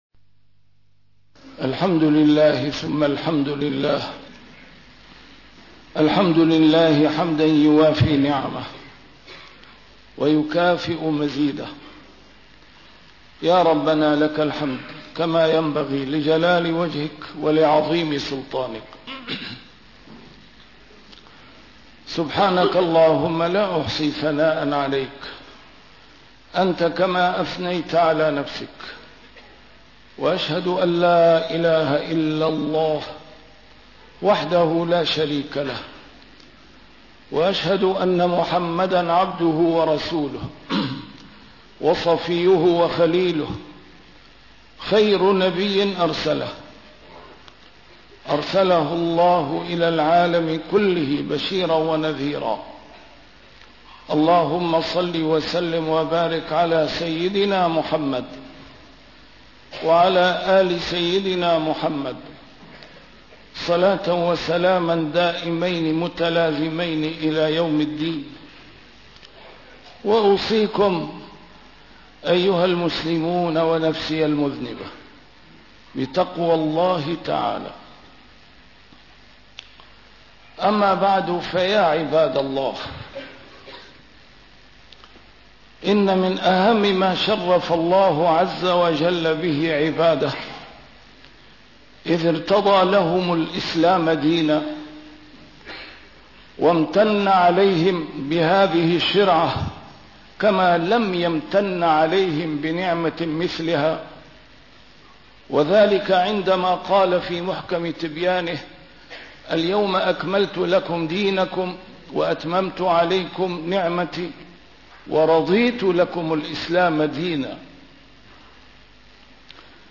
نسيم الشام › A MARTYR SCHOLAR: IMAM MUHAMMAD SAEED RAMADAN AL-BOUTI - الخطب - الخرافة منشؤها التعصب وتربتها الجهل